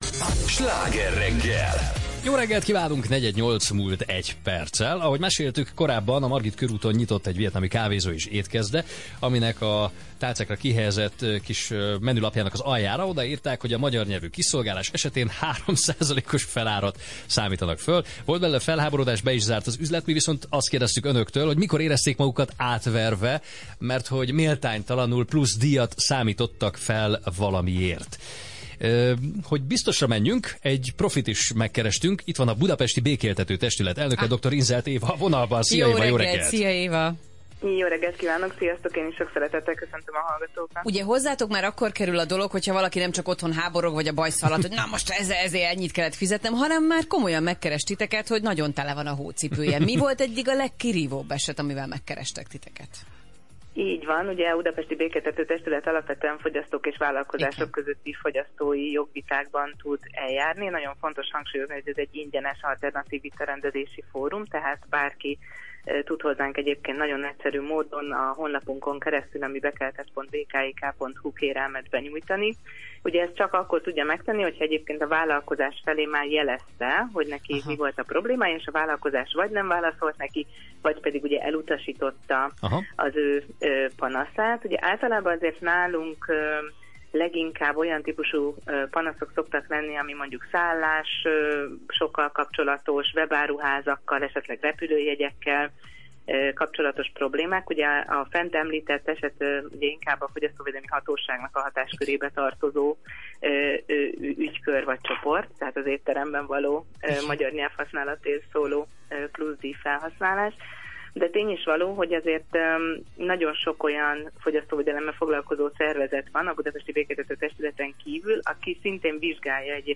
Sláger FM rádióinterjú: Tudja, kihez fordulhat? – Fogyasztói jogok röviden